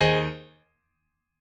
admin-leaf-alice-in-misanthrope/piano34_6_014.ogg at main